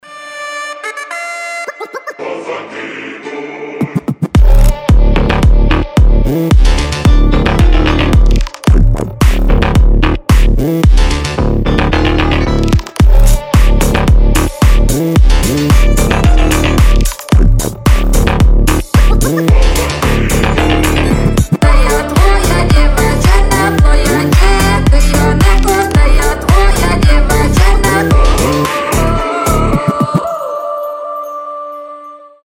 • Качество: 320, Stereo
мощные басы
Midtempo
Народные
казачьи